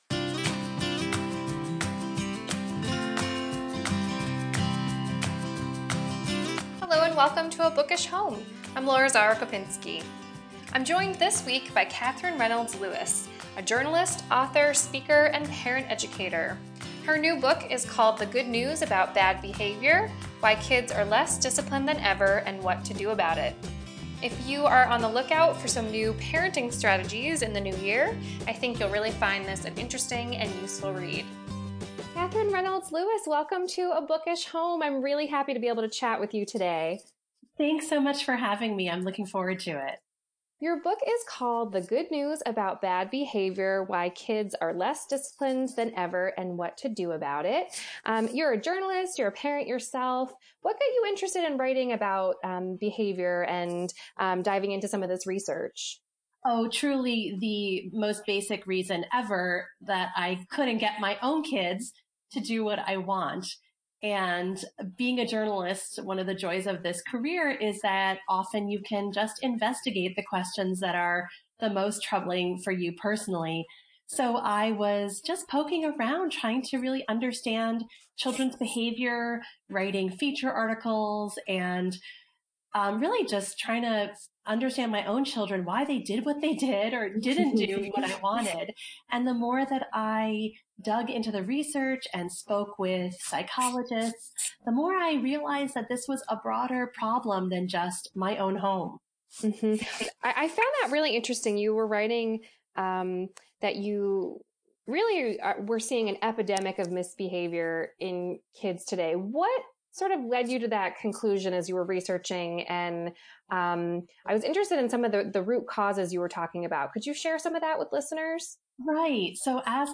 goodnewsaboutbadbehavior_finalinterview.mp3